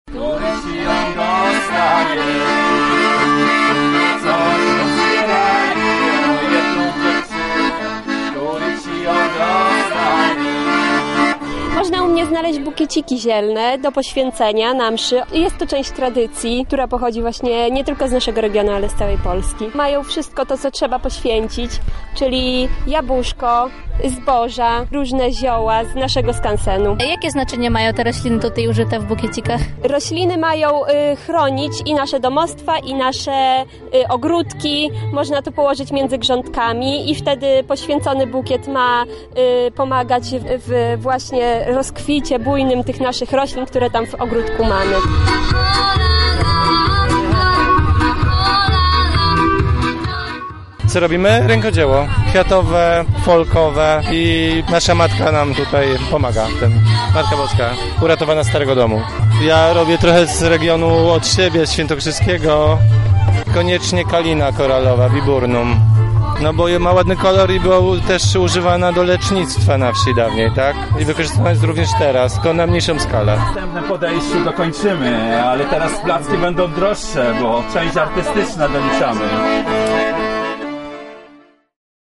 W tamtejszej parafii pod wezwaniem Wniebowzięcia Najświętszej Maryi Panny jak co roku odbywał się odpust. Na miejscu była nasza reporterka: